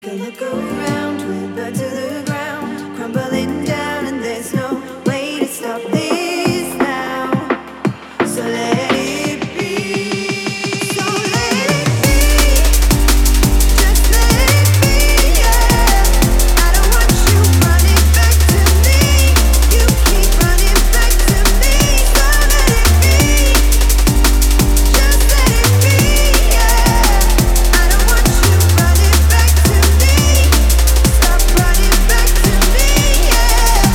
громкие
женский вокал
drum n bass
Стиль: drum'n'bass